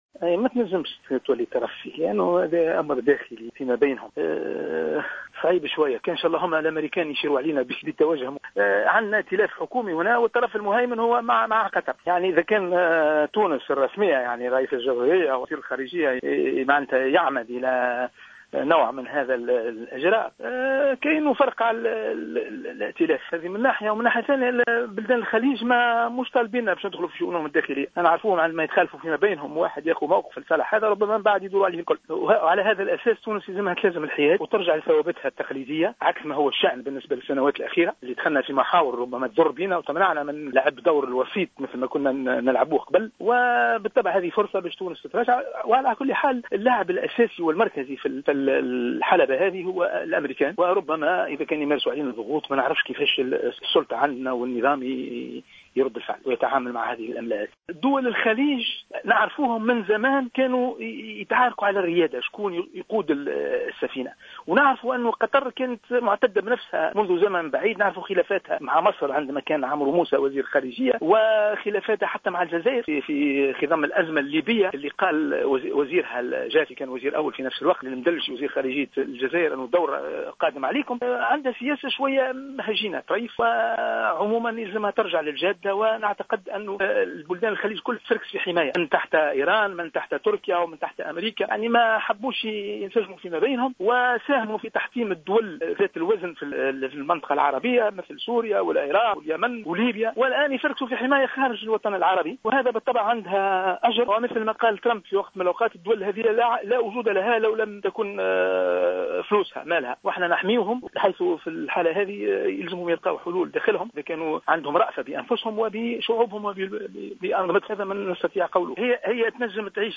في تصريح للجوهرة اف ام